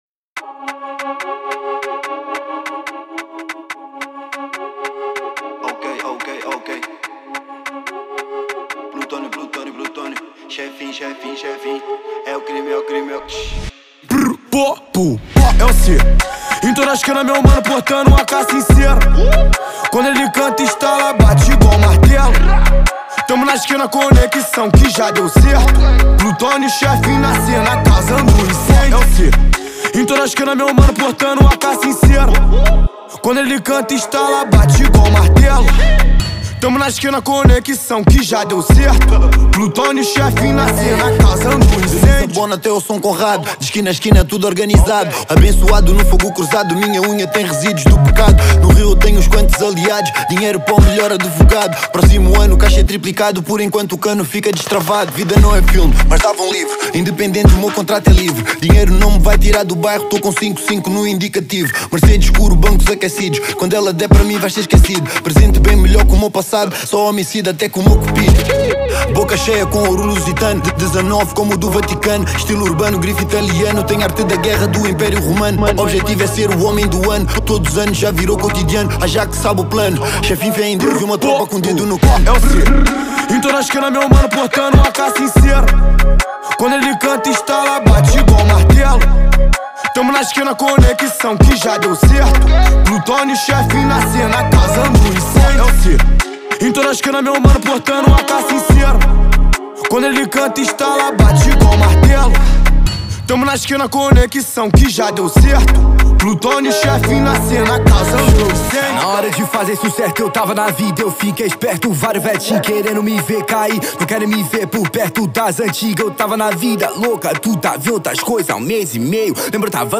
Genero: Drill